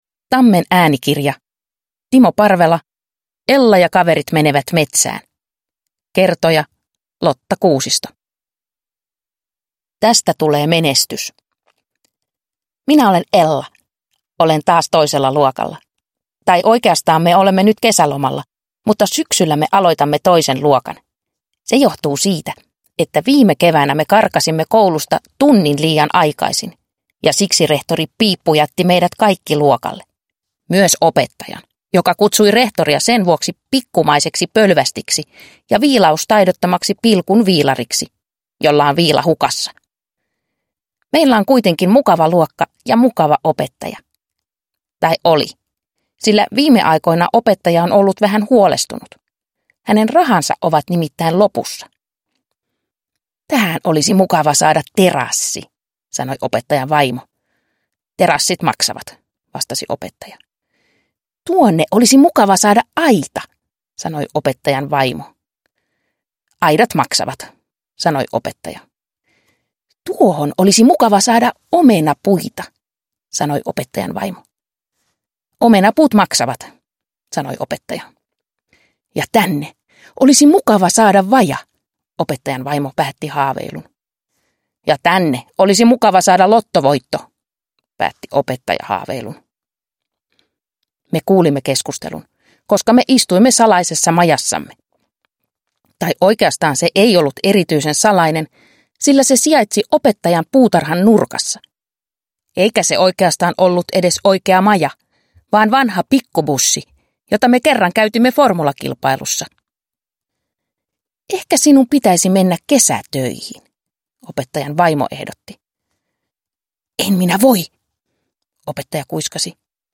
Ella ja kaverit menevät metsään – Ljudbok